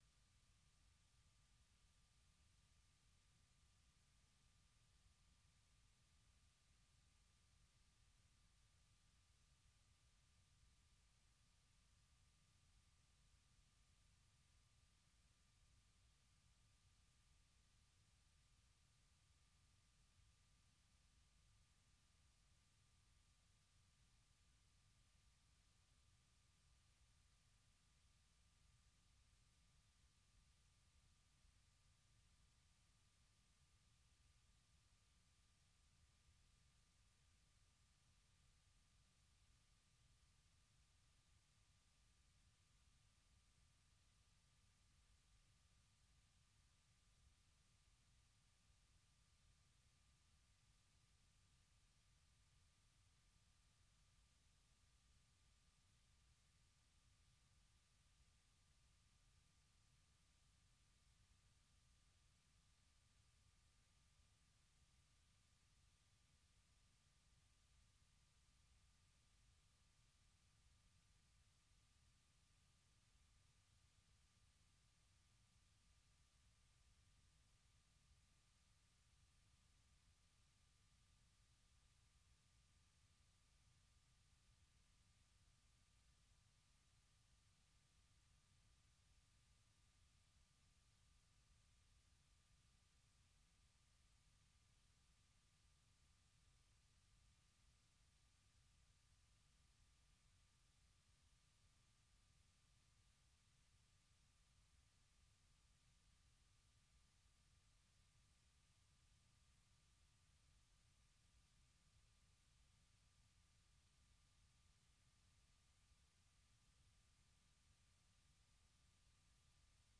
propose notamment d'écouter de la musique africaine
des reportages et interviews sur des événements et spectacles africains aux USA ou en Afrique.